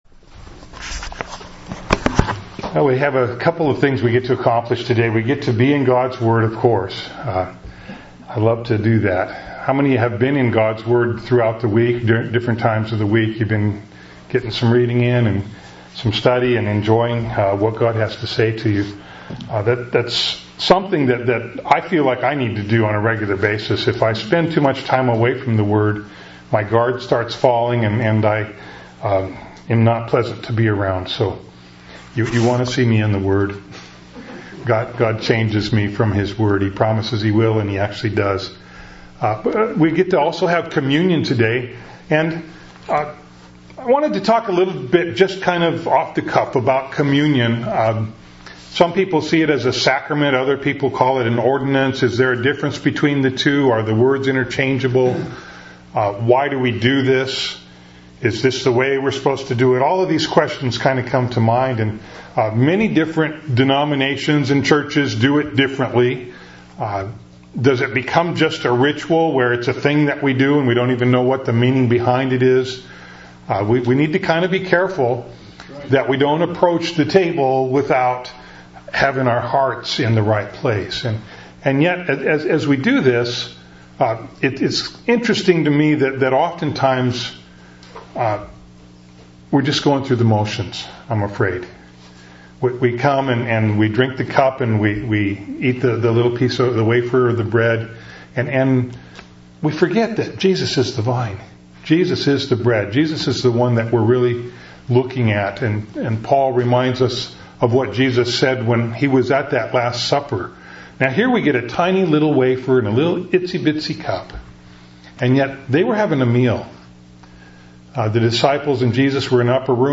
This sermon recording also includes the Communion portion of the Worship Service.
Genesis-40.1-23-and-communion.mp3